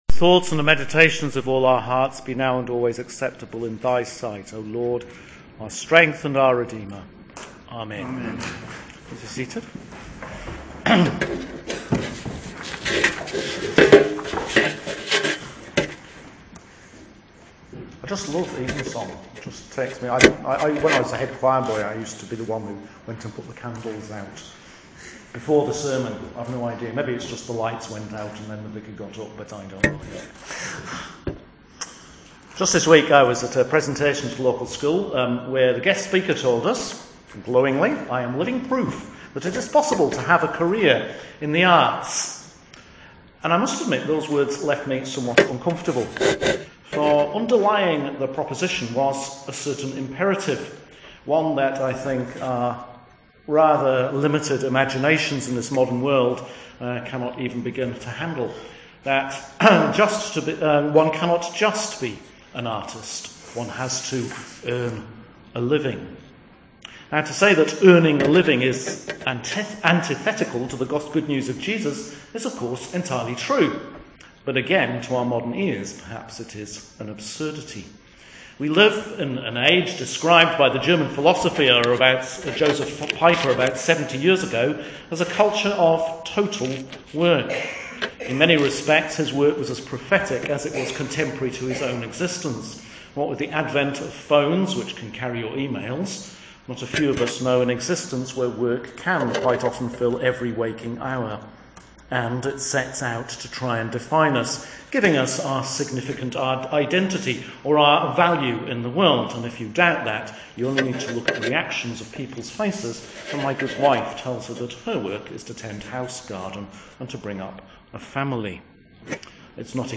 Sermon for Evensong – Sunday October 18th, 2018